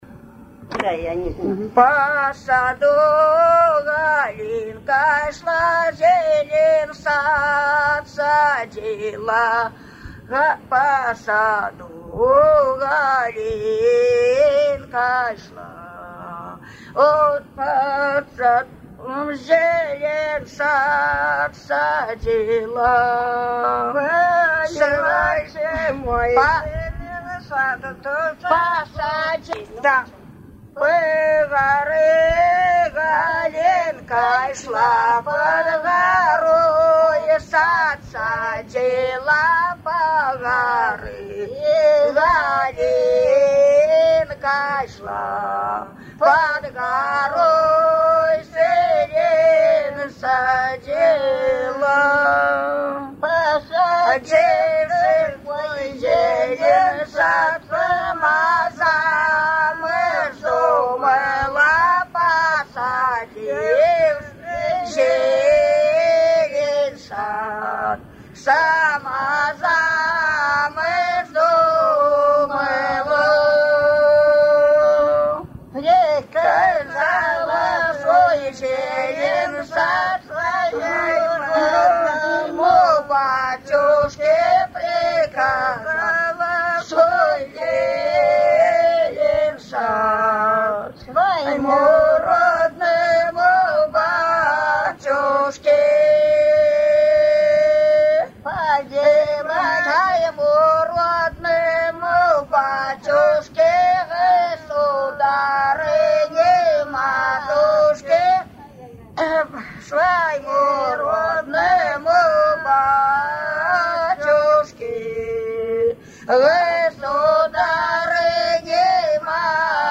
Свадебные обрядовые песни в традиции верховья Ловати
«По горы Галинка шла» Поют, когда невеста пришла из-под венца исп.